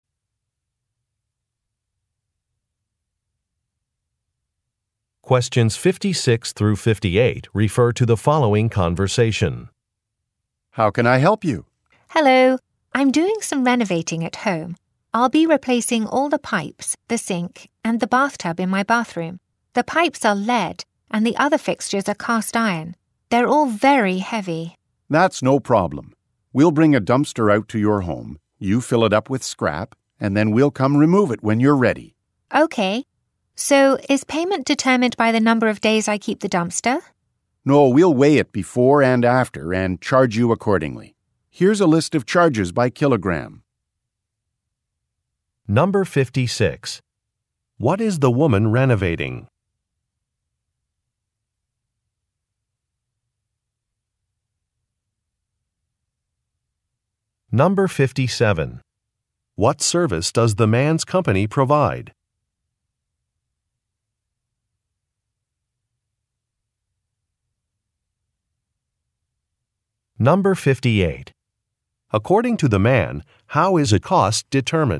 Question 56 - 58 refer to following conversation: